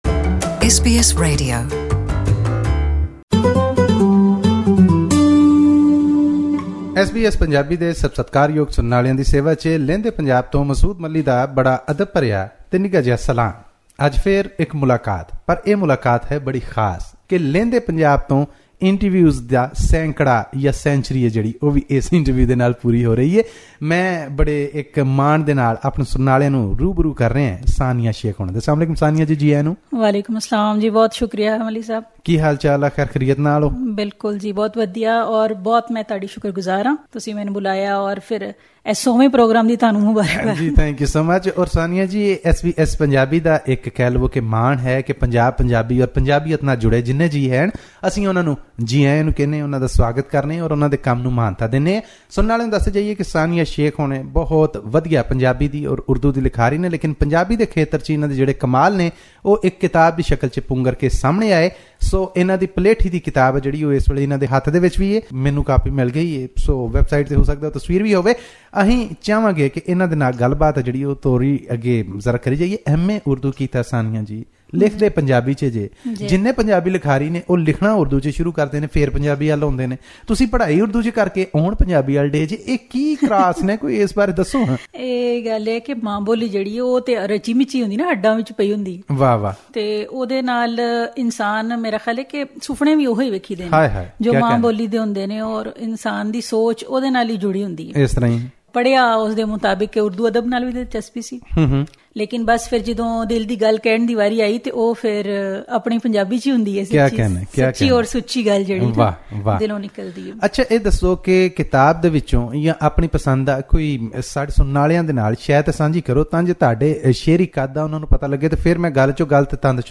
This week, we interview a poetess who's work is as simple as it is complex.